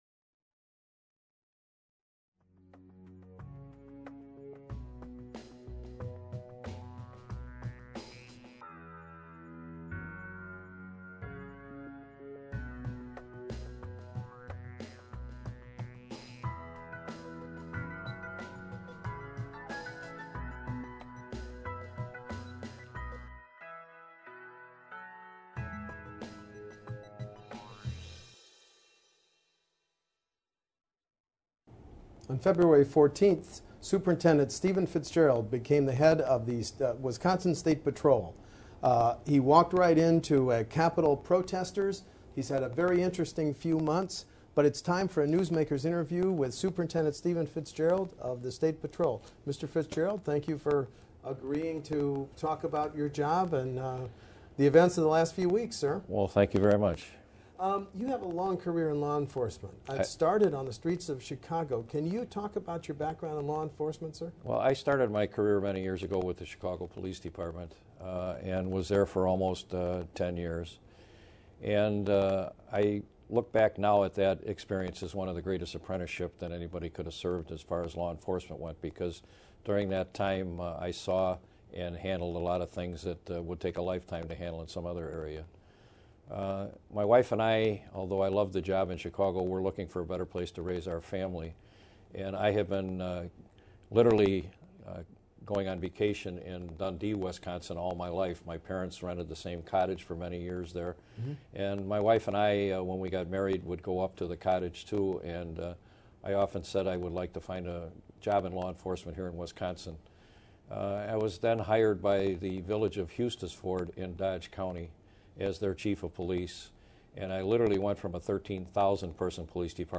In a Newsmakers interview on June 22, Fitzgerald explained why troopers became the face of Capitol security during those protests and then manned TSA-like security stations and metal detectors until June 23.